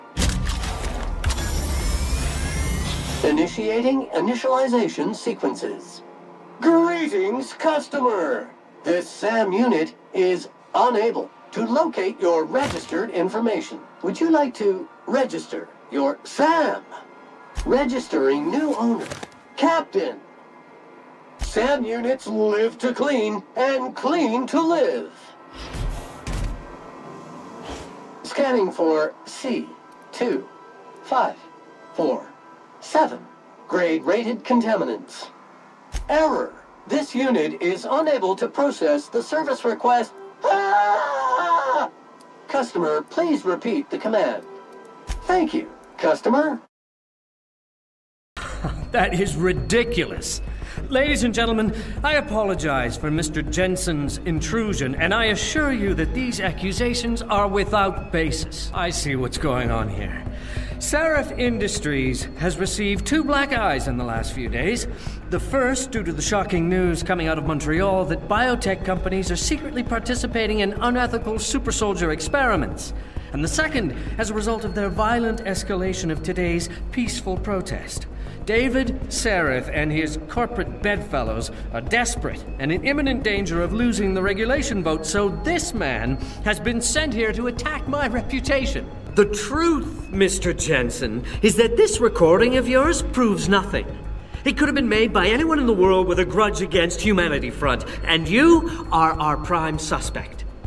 Video Games - EN